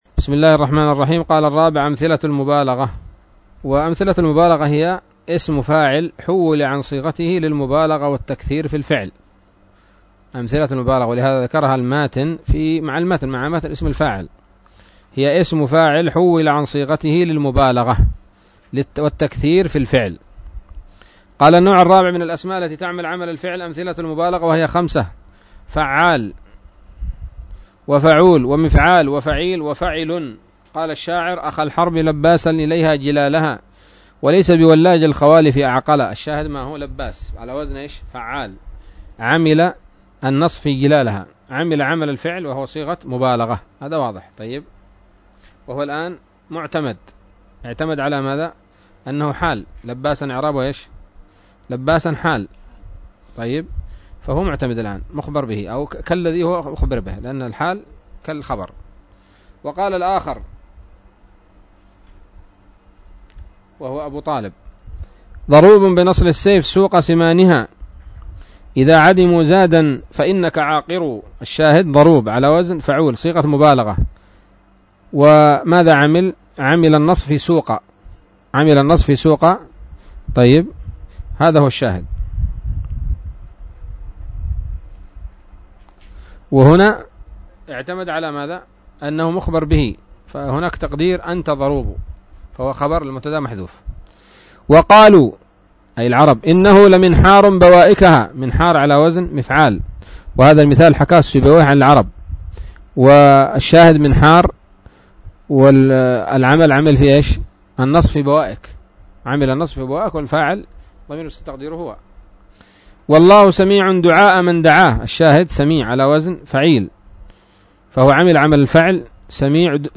الدرس التاسع بعد المائة من شرح قطر الندى وبل الصدى